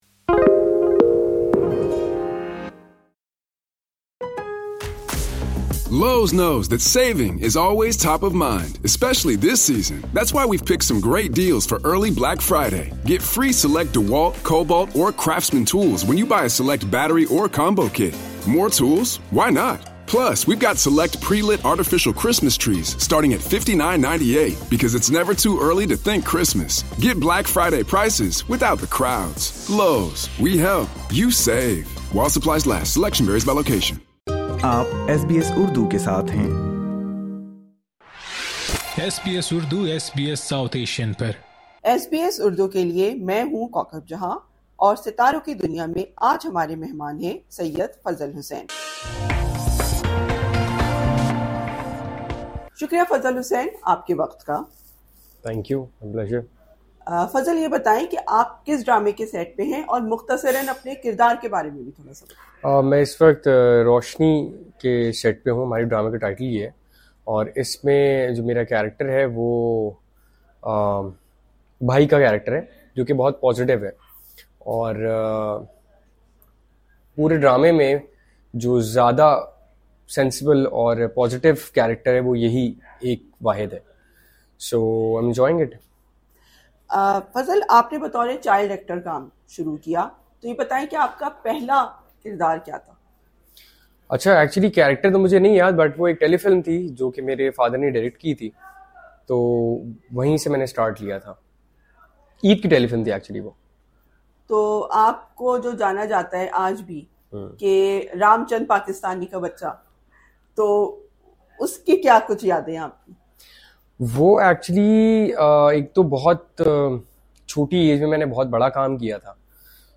ایس بی ایس کے ساتھ خصوصی انٹرویو